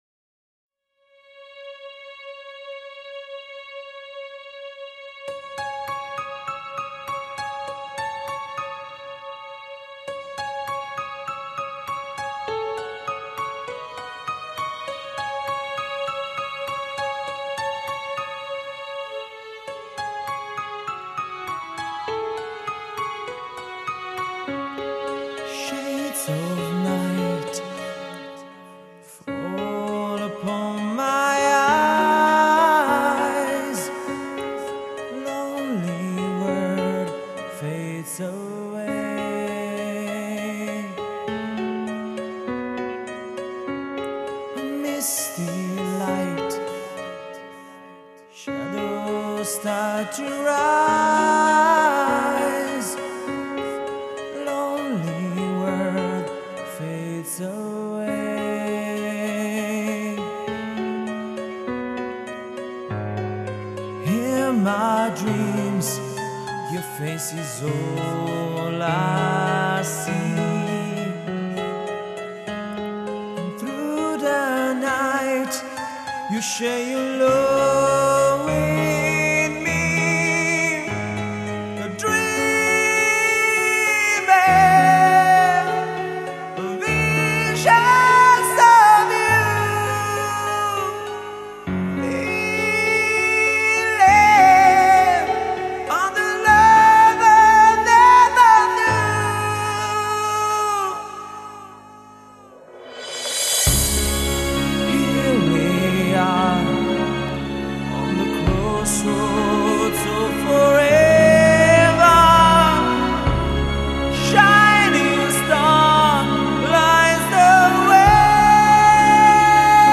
genere: Heavy Metal /Hard Rock
voce
chitarra
batteria
basso